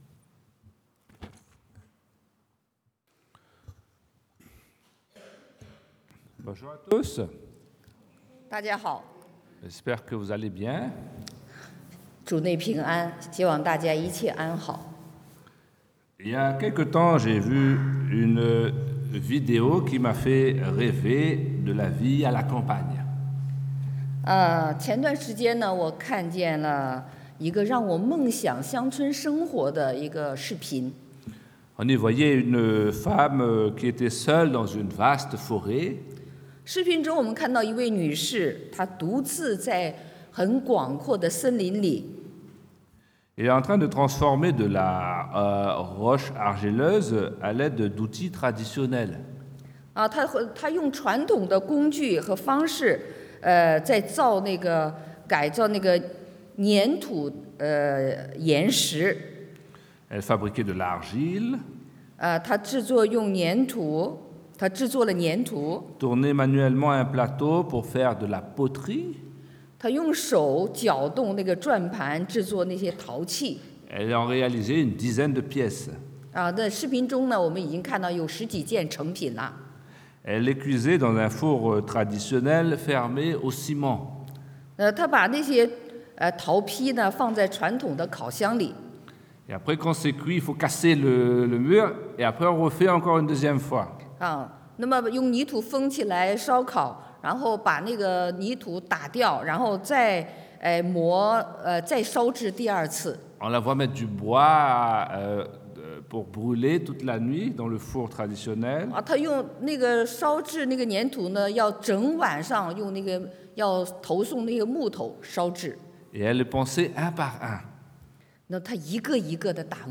Predication du dimanche « Me voici